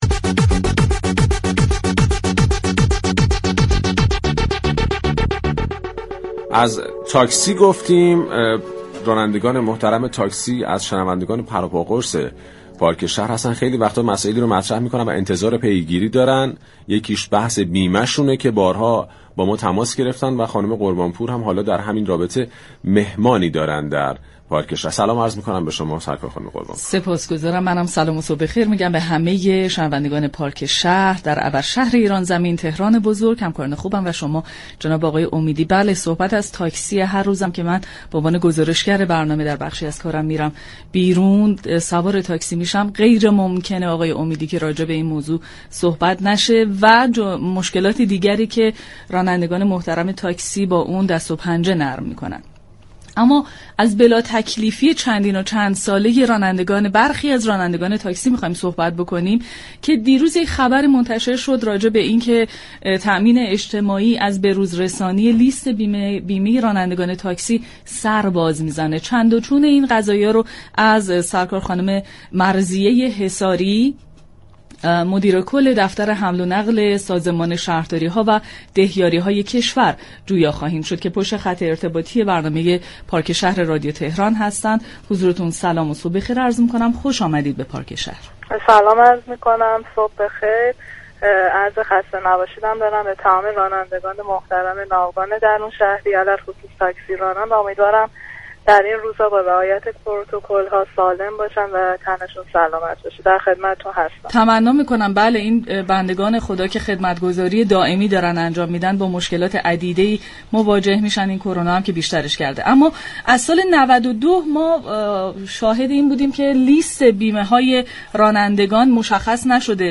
با پارك شهر رادیو تهران گفتگو كرد.